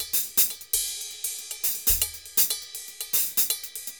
Ride_Merengue 120_1.wav